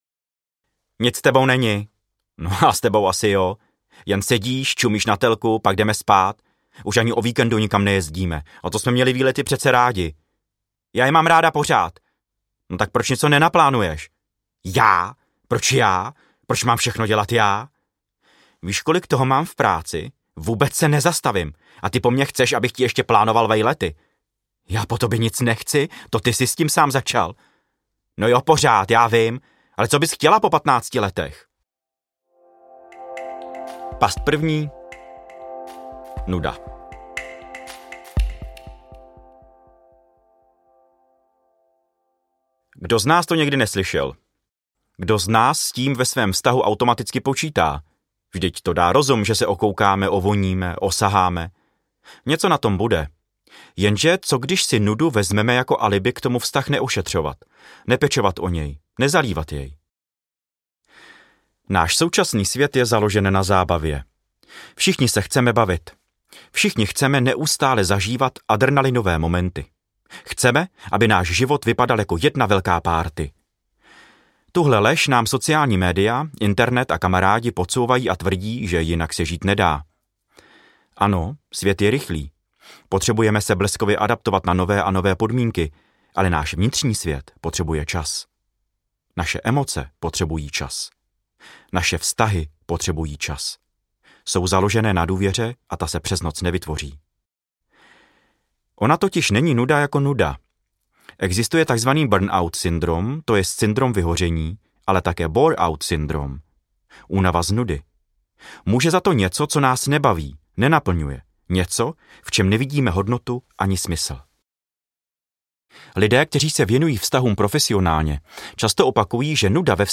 Audiokniha Vztahy a pasti